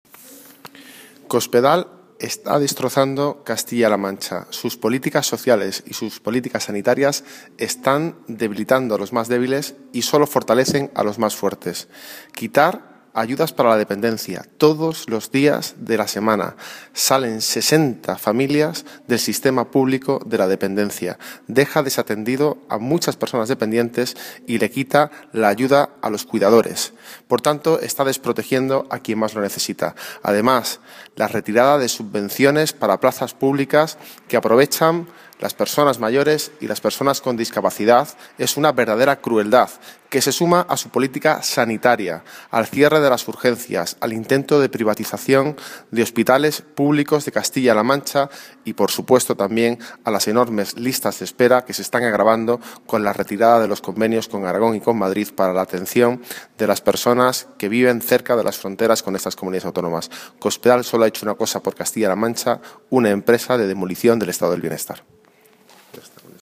Bellido ha hecho esta afirmación durante un acto organizado por la Agrupación Local del PSOE de Torrejón del Rey (Guadalajara) para expresar el rechazo de este partido al deterioro de las políticas públicas de sanidad y bienestar social, al que ha asistido la secretaria de Política Social de la Ejecutiva Federal, Trinidad Jiménez.
pablo_bellido__acto_torrejon_del_rey.mp3